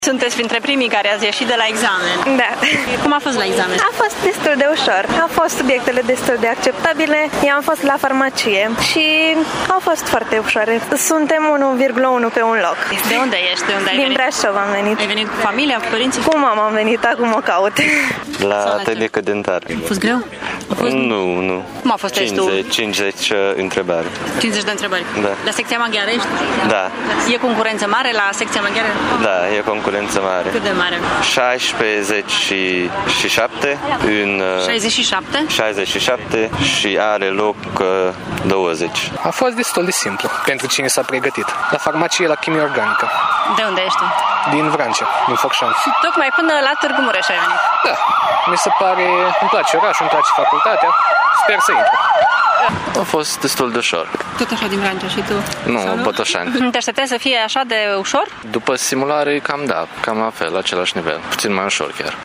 Candidații, majoritatea veniți din alte județe ale țării, au fost mai puțin stresați, mai ales că examenul a fost mai ușor decât se așteptau: